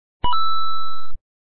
掉落音效.mp3